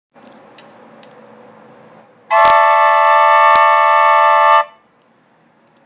4 tin whistles
Inspired by the Mexican quadruple flutes I finally found whistles small enough to be able to play 4 simultaneously.
Simple chords:
D E A Cis
D-E-A-Cis.wav